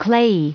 Prononciation du mot clayey en anglais (fichier audio)
Prononciation du mot : clayey